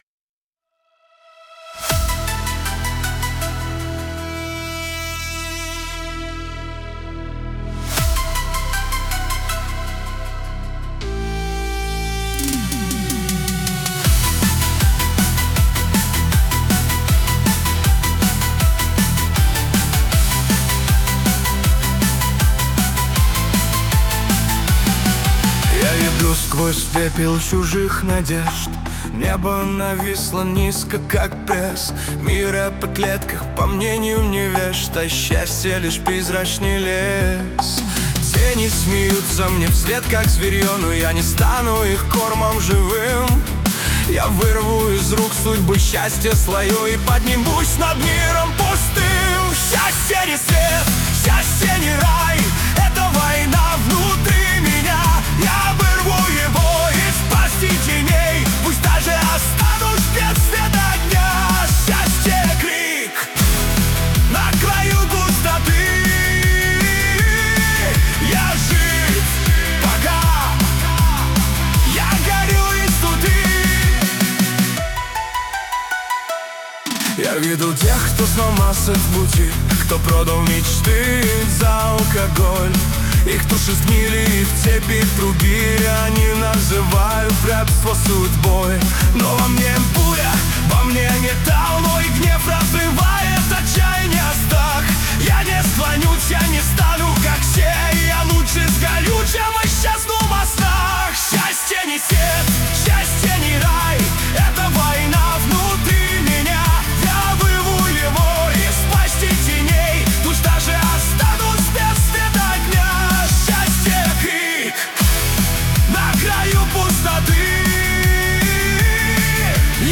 хэви-металл, средний темп, электрогитара риффами, грязный тембр, короткие повторяющиеся фразы, уплотнение гитары в припеве, плотная ритм-секция
• гитара получила манеру игры (риффы);
• появился тембральный якорь (грязный звук);